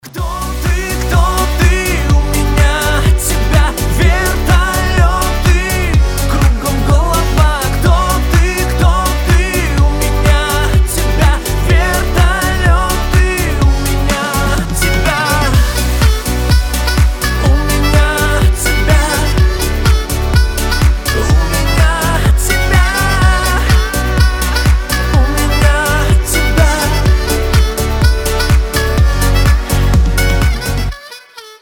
• Качество: 320, Stereo
поп
веселые
губная гармошка